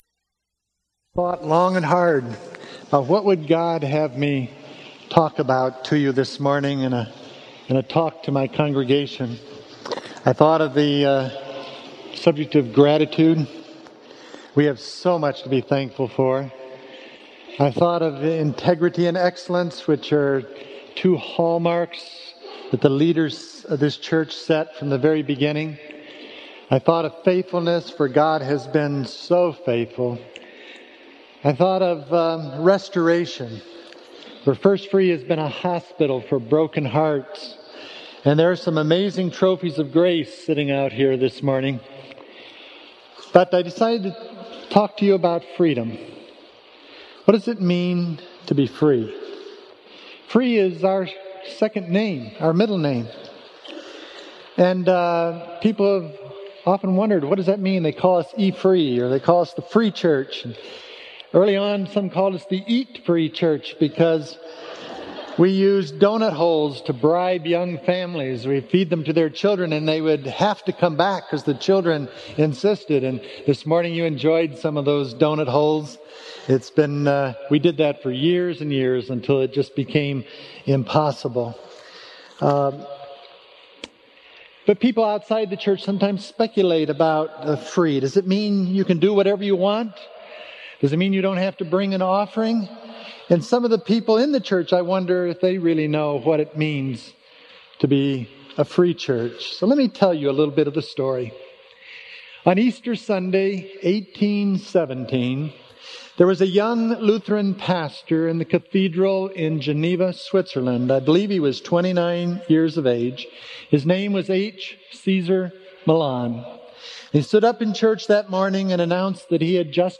Twentieth Anniversary Service of First Free, St. Louis